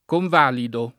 convalido [ konv # lido ]